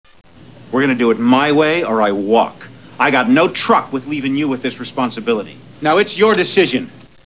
. . . FROM THE MOVIE "Negotiator" . . .